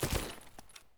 3098b9f051 Divergent / mods / Soundscape Overhaul / gamedata / sounds / material / actor / step / earth1.ogg 36 KiB (Stored with Git LFS) Raw History
earth1.ogg